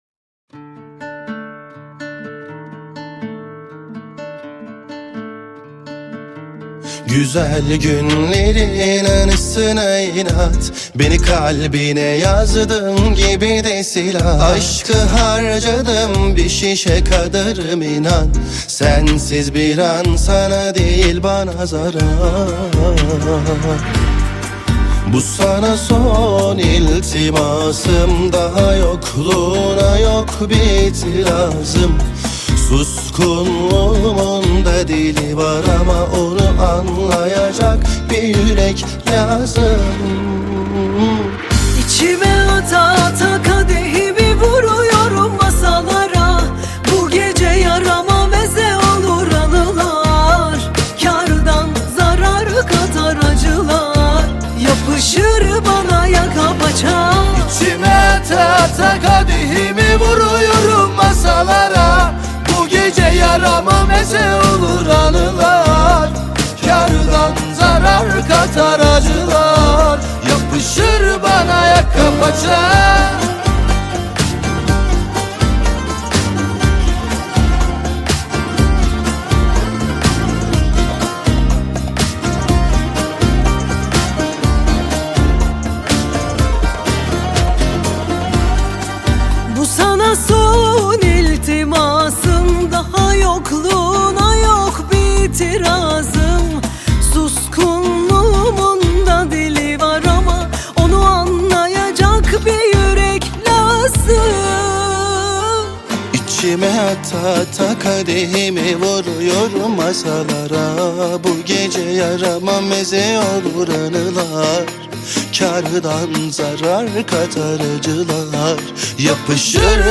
( صدای زن و مرد ترکیبی )